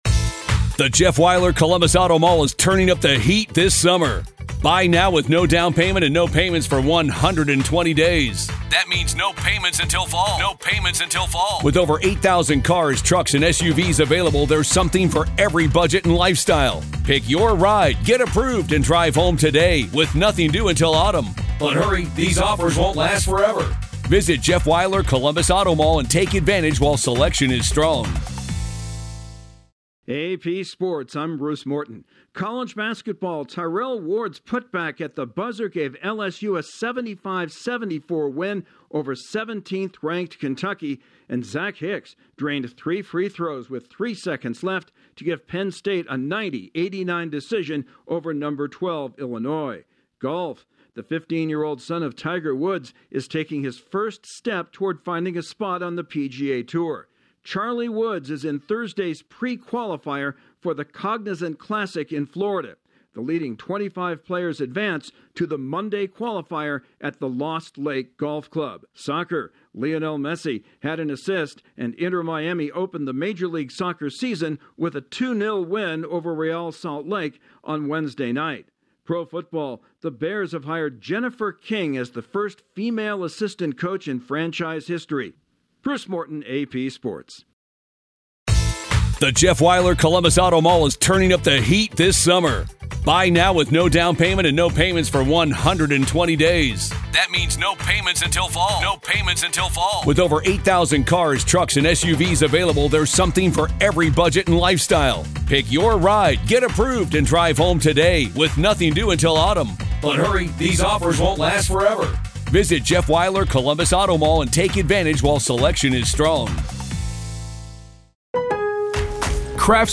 Sports News from the Associated Press / AP Sports SummaryBrief at 11:49 p.m. EST